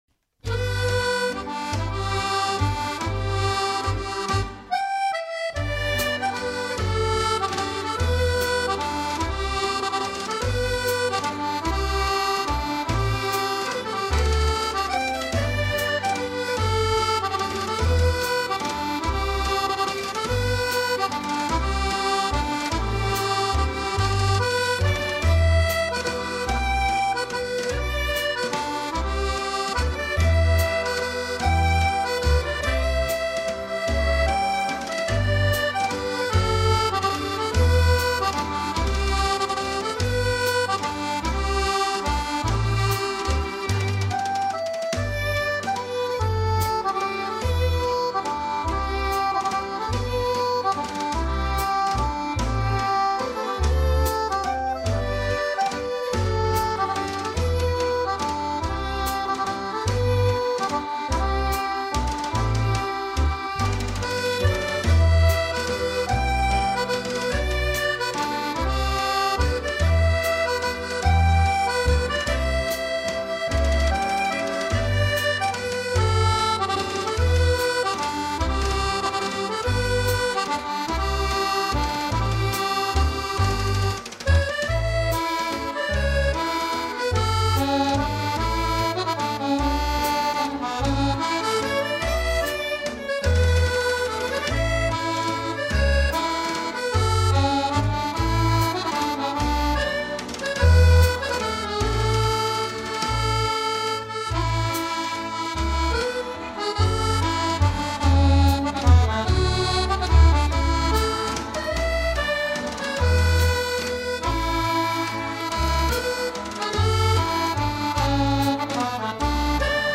Genre: Contemporary Folk.